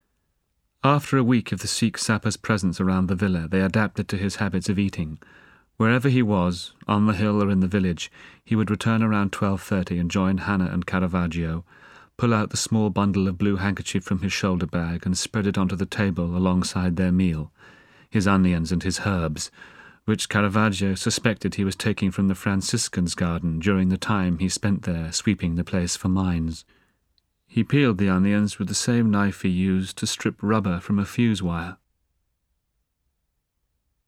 【有声英语文学名著】英国病人 35 听力文件下载—在线英语听力室